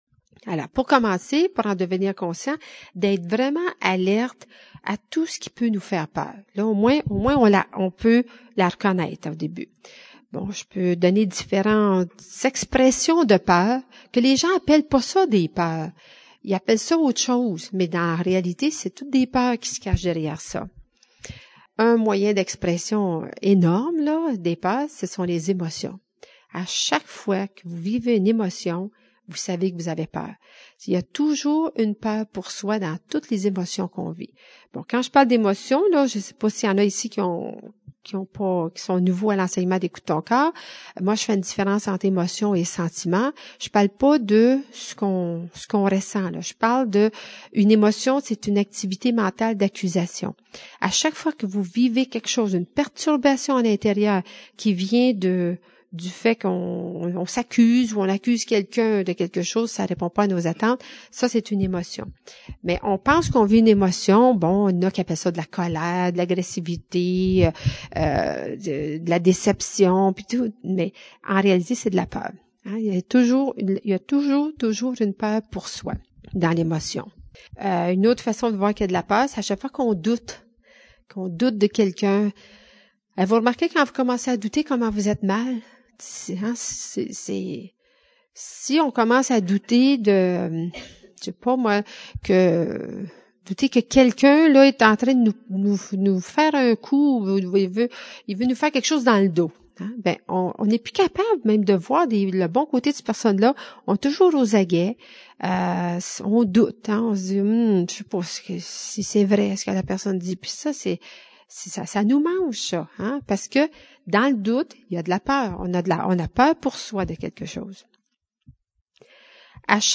4) Conférence de Lise Bourbeau « Comment gérer ses peurs »